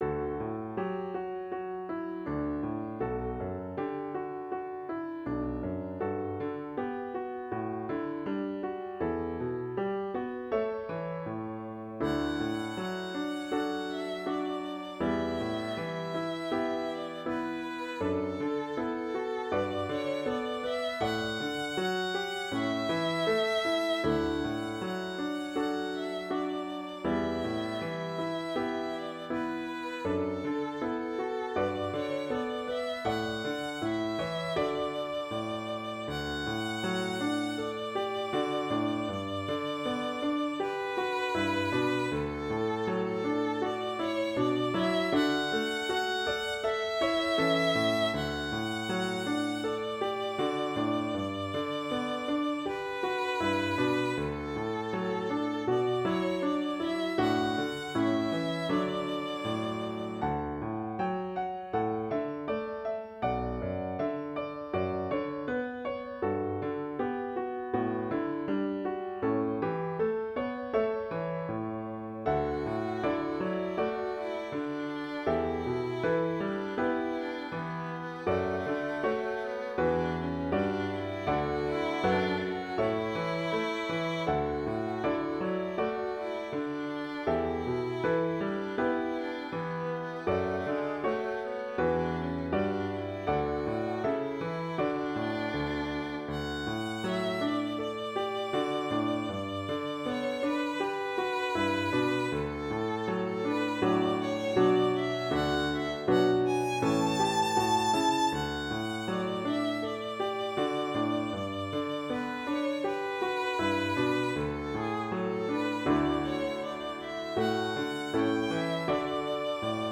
Intermediate Instrumental Solo with Piano Accompaniment.
Christian, Gospel, Sacred.
puts the sacred theme to a gentle, meditative mood